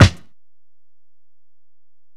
Snare (13).wav